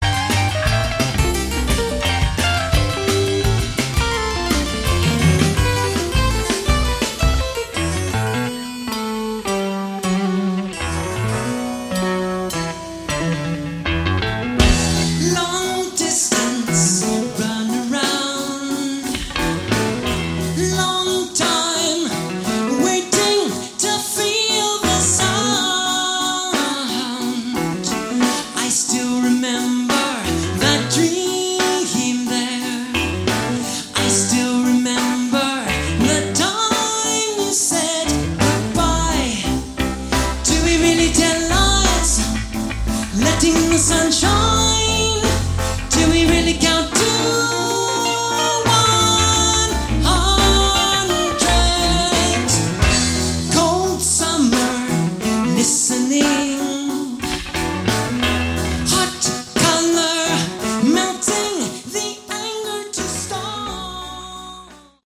Category: Prog Rock
vocals
guitars
bass
drums
keyboards